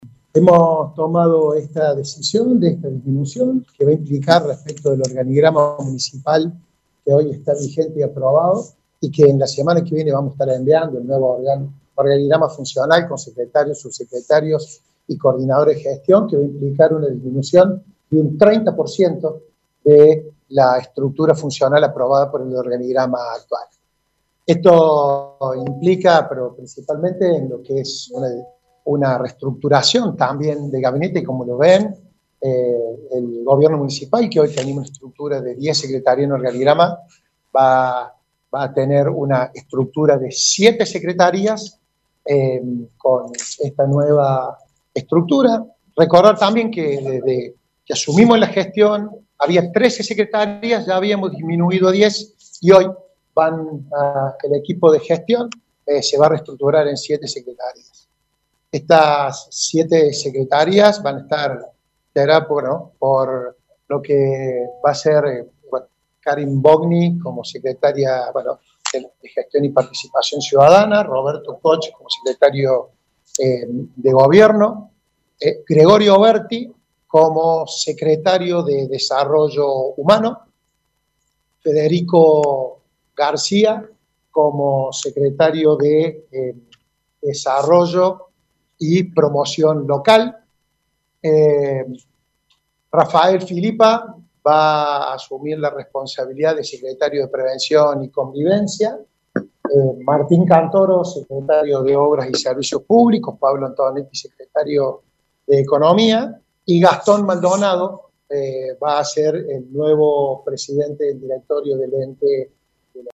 En conferencia de prensa, el intendente dijo que se trata de una reducción del 30% en la estructura con cambios en algunas carteras.